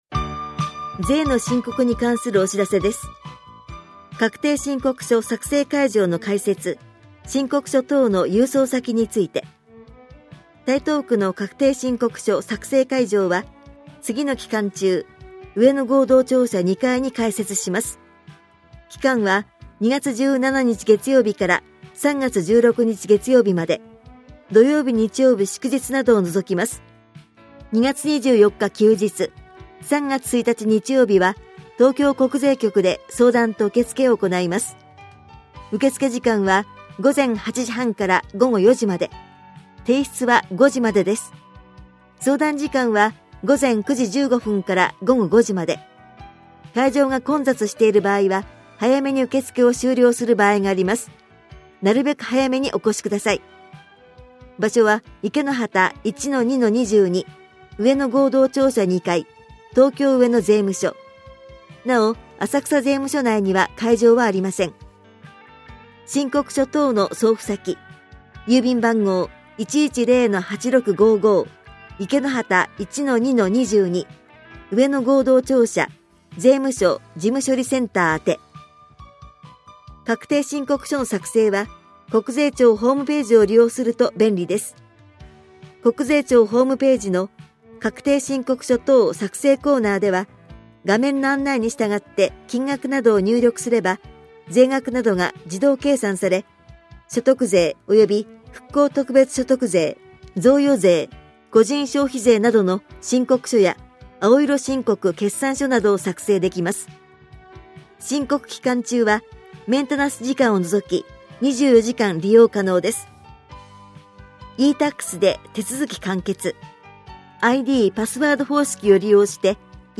広報「たいとう」令和2年1月20日号の音声読み上げデータです。